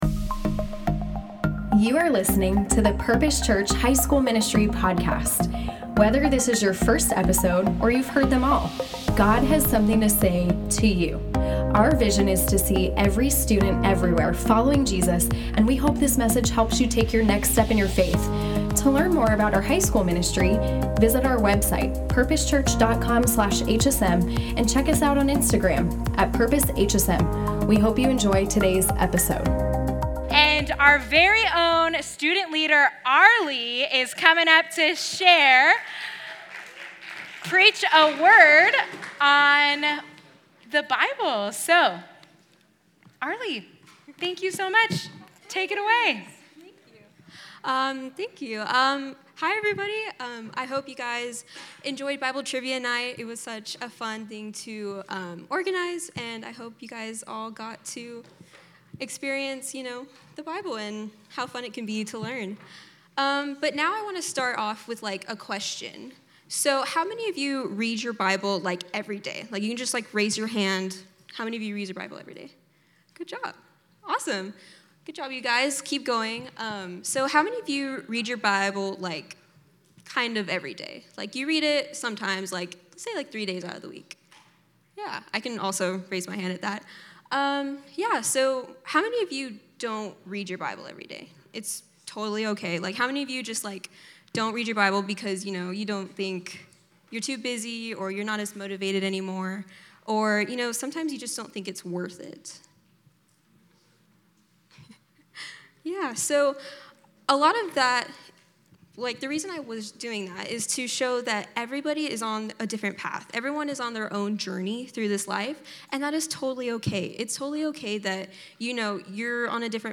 Bible Trivia Night: Devotional